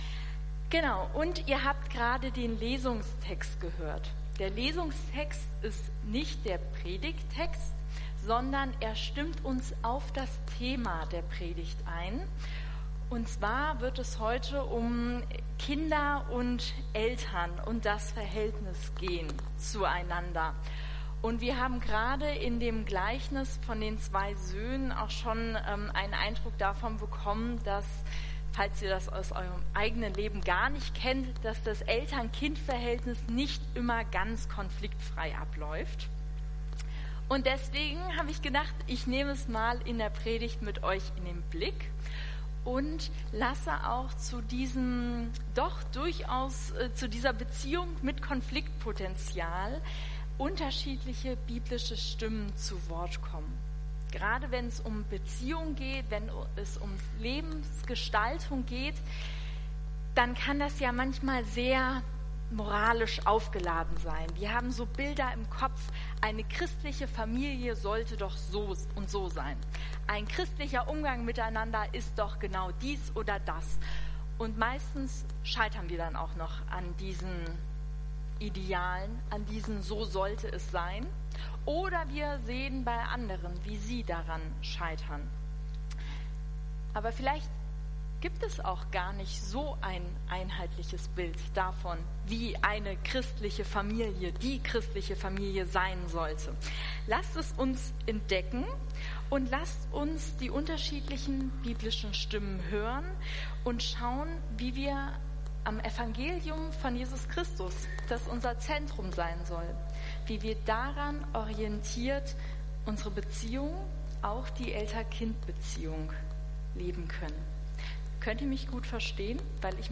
Predigt Podcast FeG Wuppertal Vohwinkel